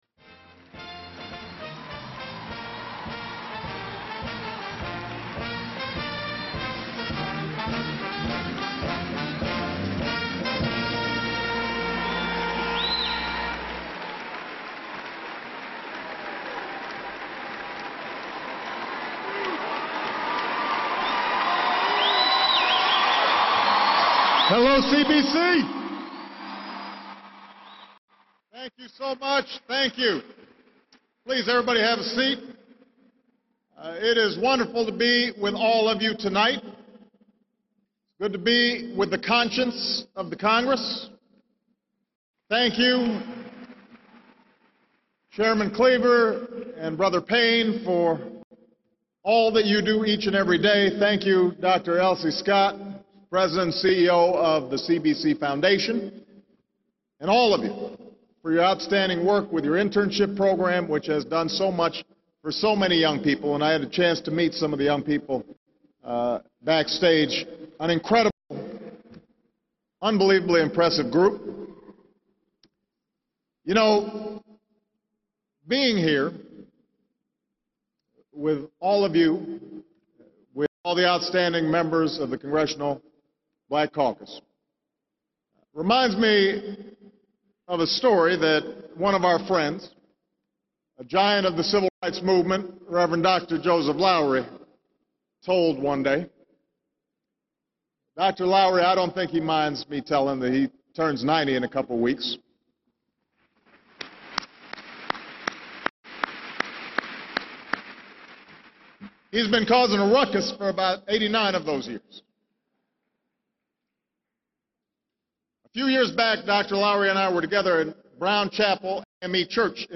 U.S. President Barack Obama addresses the 41st Congressional Black Caucus Foundation Annual Legislative Conference
President Barack H. Obama speaks at the Congressional Black Caucus Foundation's annual legislative conference in Washington, D.C. Obama praises the work of the Caucus, invokes memories of Civil Rights leaders, and advocates for the passage of the American Jobs Act. He recounts the effects of the recession and declares his administration's goal is to help those less fortunate.